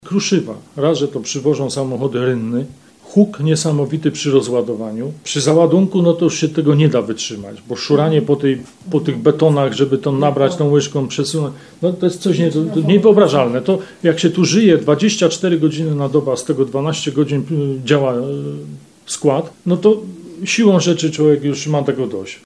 Kiedy się tu żyje 24 godziny na dobę, a z tego 12 godzin działa skład, to siłą rzeczy człowiek ma już tego dość – opisuje jeden z mieszkańców.